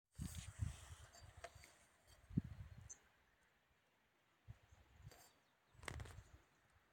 Putni -> Ķauķi -> 8
Ziemeļu ķauķītis, Phylloscopus borealis
Piezīmes/Ejot no jūras pa taku saklausīti asi 'z' skaņas saucieni, aptuveni 10 saucienu sērijā uzreiz ar lielu aizdomu par PHYBOR, pēc aptuveni piektā sauciena novērots no 12m ķauķītis aiz lapām izteikti pelēcīgs ar olīvzaļo nokrāsu mugurā, bez saskatāmiem izteiktiem dzeltenīgajiem/oranžīgajiem toņiem priekšpusē, pagriežot galvu ārā no lapām izteiktu kontrastējošu garu uzacs svītru, un labi saskatāmu spārna svītru, uzreiz pēc aptuveni 5 sekunžu vērojuma metu nost binokli un ņēmu telefonu ierakstīt saucienu, paceļot telefonu iezib dziļāk krūmā un sākot iekastīšanu apklust, līdz pārlido pār taku uz Z kur lidojumā pēdējo reizi nobļaujoties (ierakstīts) pazūd aiz krūma.